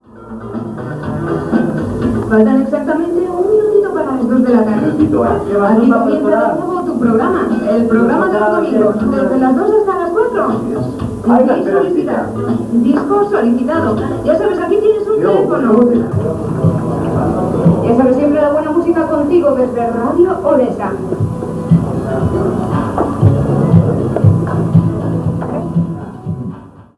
Presentació del programa amb identificació de la ràdio
Musical
FM